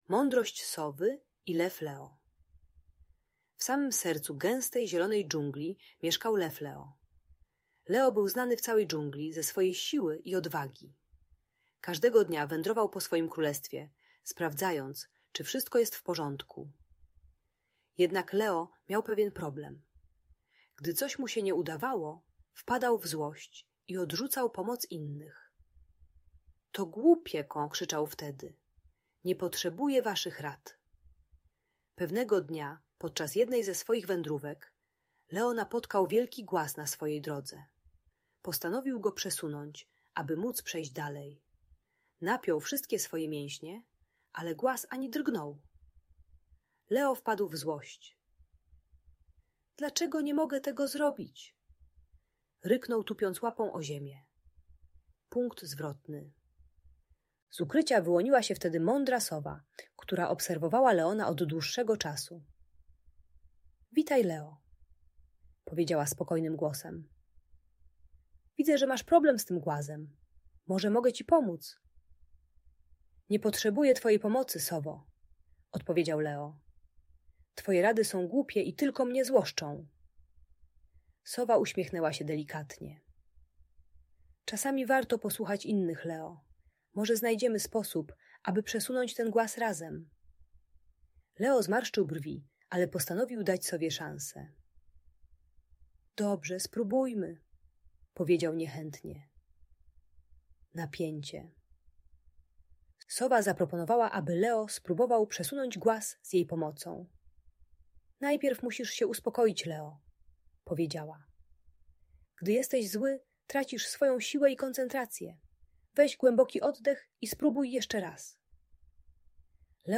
Mądrość Sowy i Lew Leo - Piękna opowieść o sile i mądrości - Audiobajka